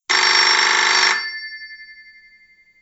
Cal que el fitxer estigui gravat al disc CIRCUITPY en format wav monoaural (no estereofònic) amb una freqüència de mostreig més petita o igual de 22 kHz amb format 16 bit PCM.
ring.wav